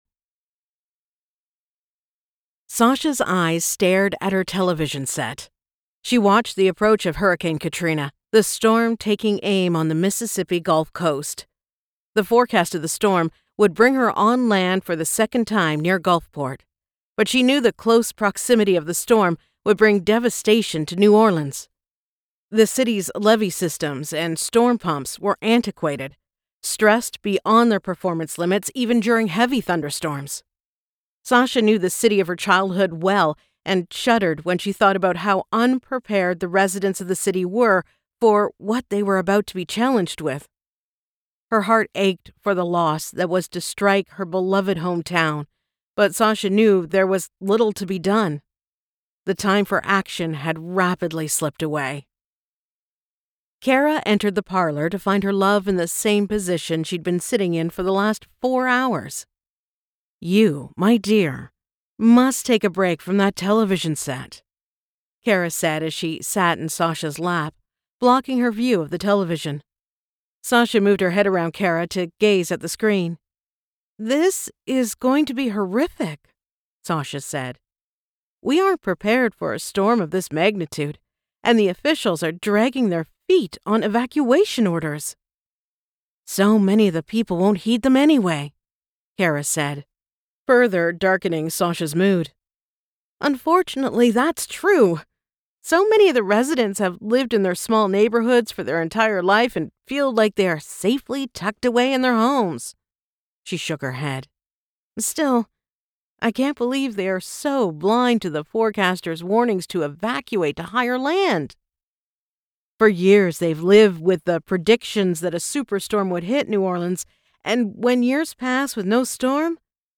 Line of Sight-Sasha Thibodaux Series Book 3 by Ali Spooner [Audiobook]